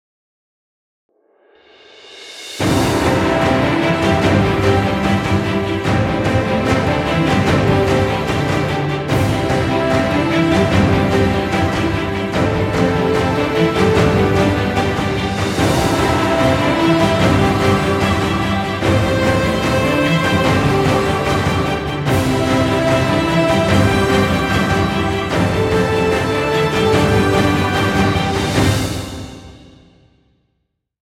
Background Music Royalty Free.